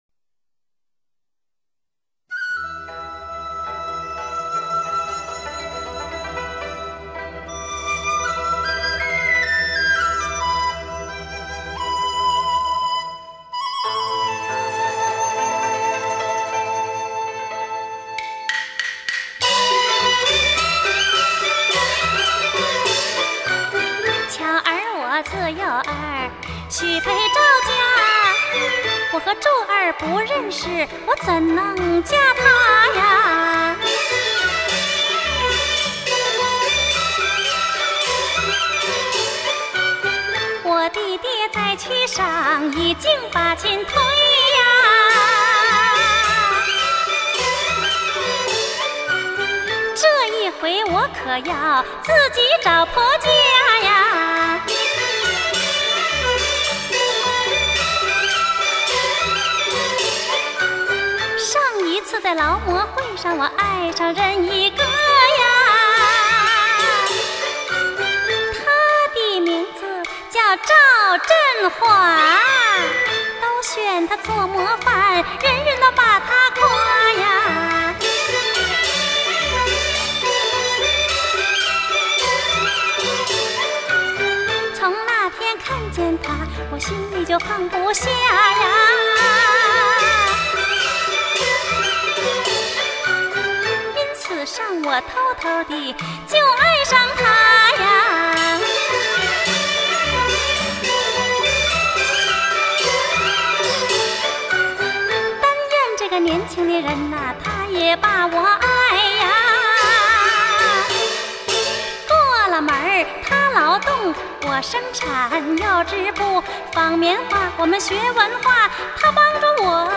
评剧